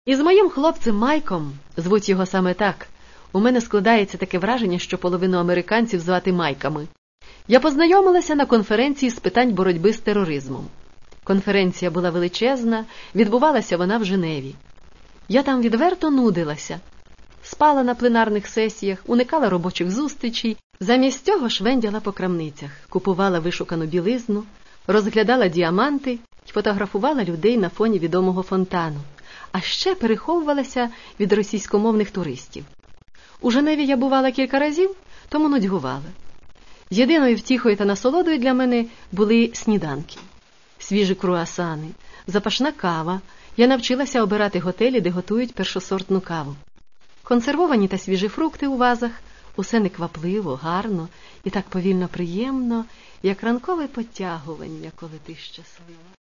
Catalogue -> Audio Books -> Modern